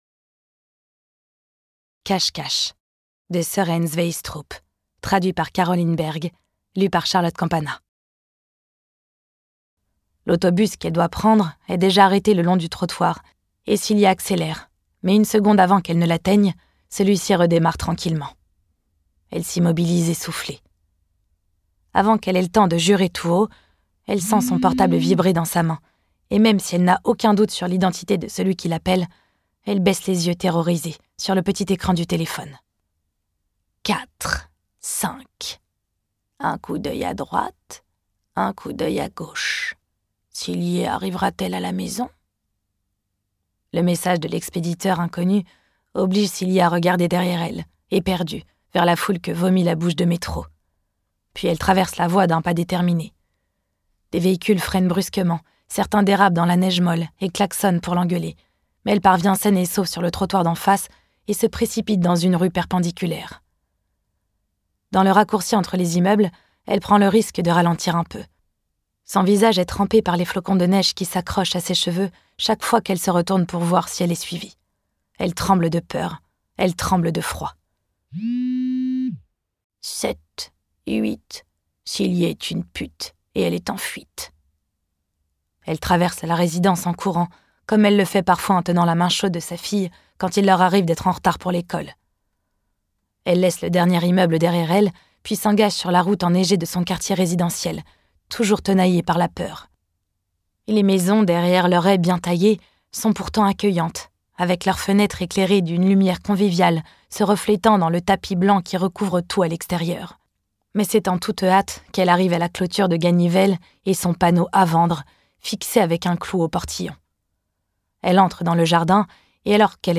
Diffusion distribution ebook et livre audio - Catalogue livres numériques
Après Octobre, le maître du thriller danois Søren Sveistrup revient avec un scénario fracassant qui confronte le duo d’enquêteurs Mark Hess et Naia Thulin à l’une des affaires les plus complexes et les plus glaçantes de leur carrière. Interprétation humaine Durée : 15H40 26 , 95 € Ce livre est accessible aux handicaps Voir les informations d'accessibilité